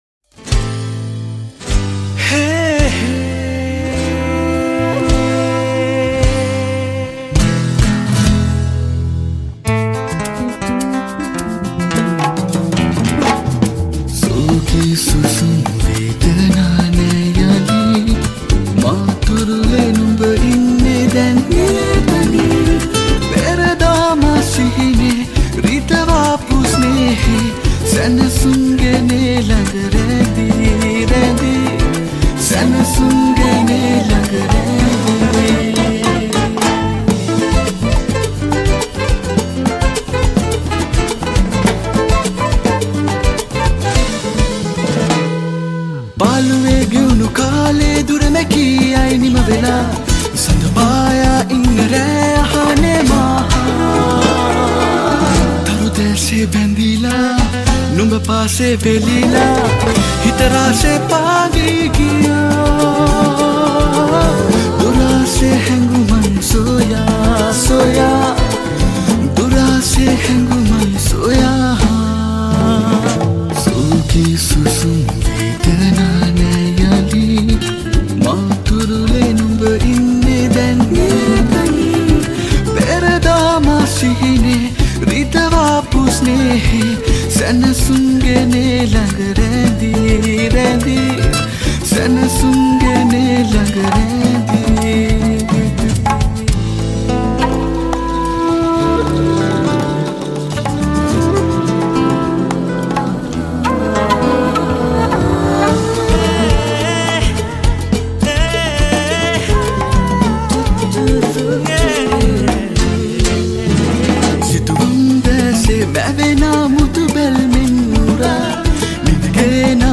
High quality Sri Lankan remix MP3 (4.2).